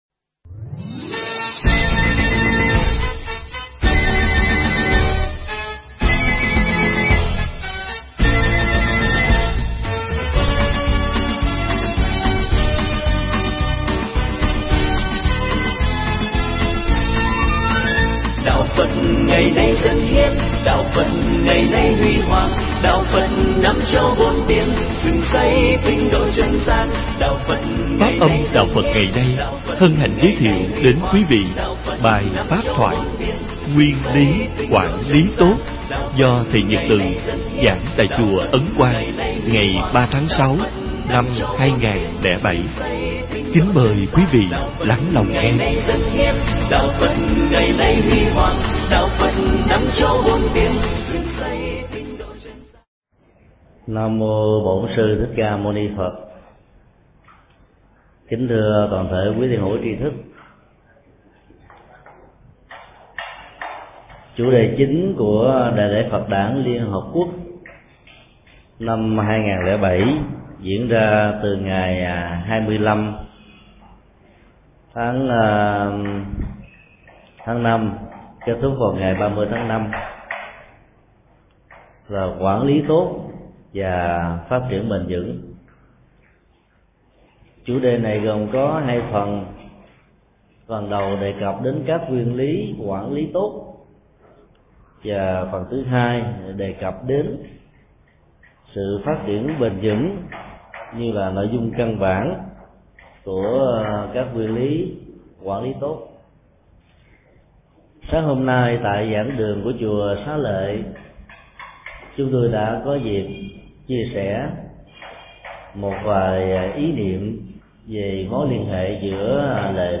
Nghe mp3 thuyết pháp Nguyên lý quản lý tốt được thầy Thích Nhật Từ thuyết giảng tại Chùa Ấn Quang, ngày 03 tháng 06 năm 2007.